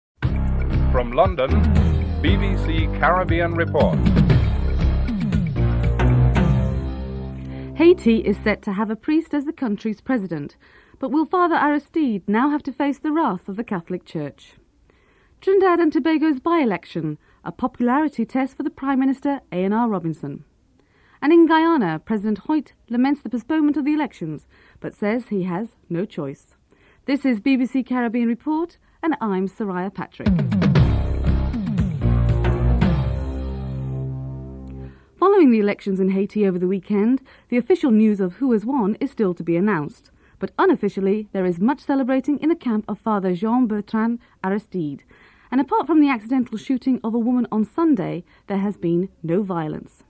Headlines (00:00-00:33)